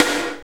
NJS SNR 13.wav